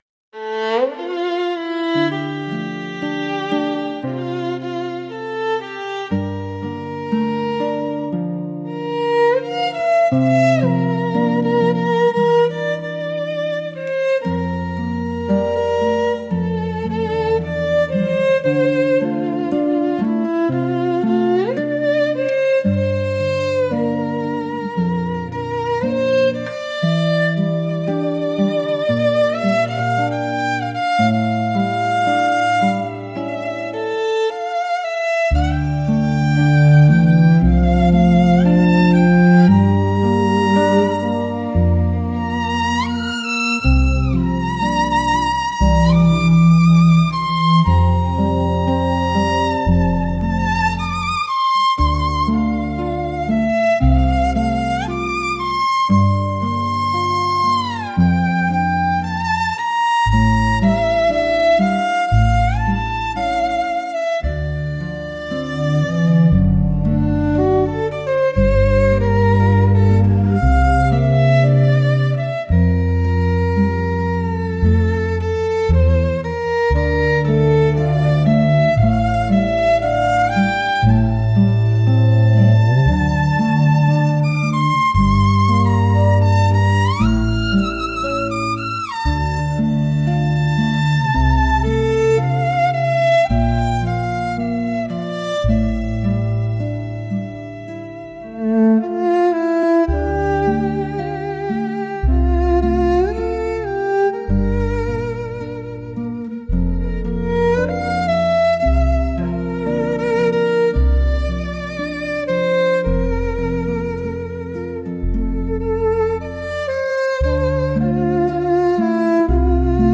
Instrumental 5